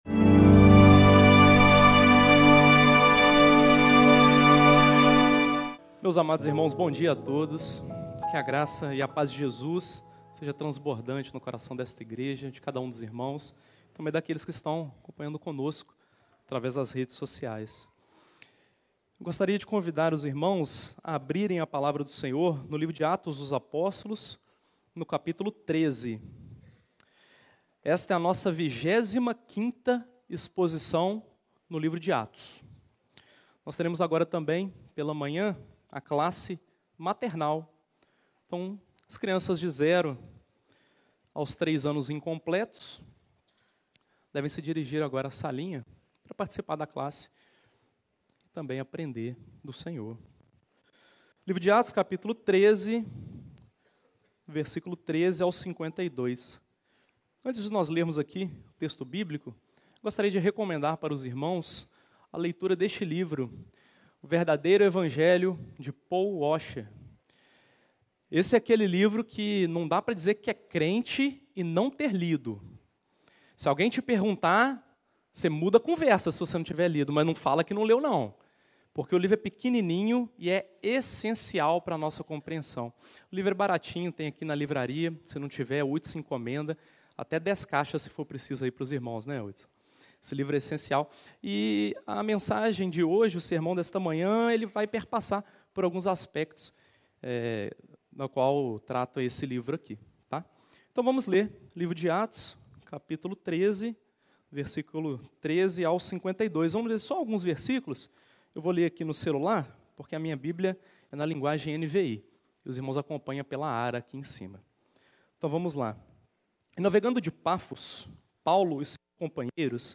Explorando a pregação do Evangelho em Antioquia da Pisídia, o sermão destaca o poder transformador da mensagem bíblica, capaz de gerar quebrantamento ou endurecimento nos corações, enfatizando a escolha pessoal diante do impacto do Evangelho.